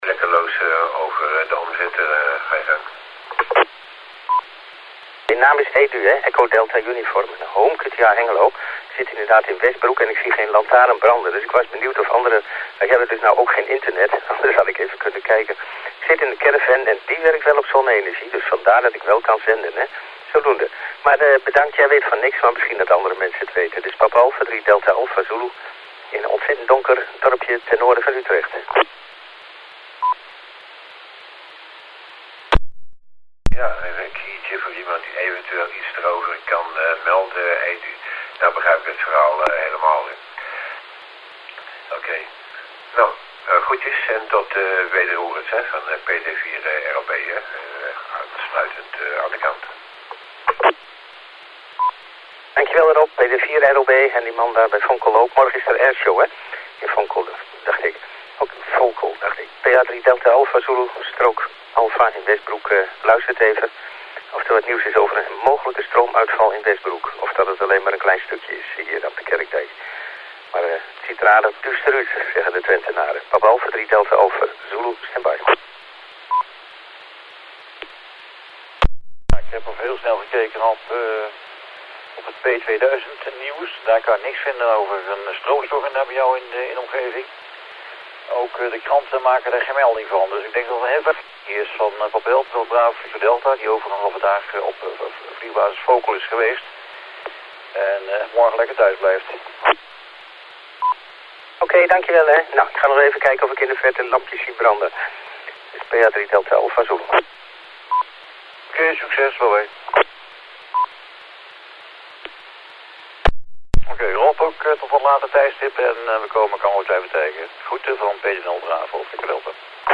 It still is somewhat sharp, but that can be a good thing for narrowband signals as we use it. Here are some samples, which are recorded directly from the speaker output socket.
You hear a repeater with medium signal strength.
UV-5RE – VHF reception repeater medium signalstrength audio sound on speaker output:
When the squelch mutes, you hear a “plop”.
UV-5RE-VHF-reception-repeater-medium-signalstrength-audio-sound-on-speaker-output.mp3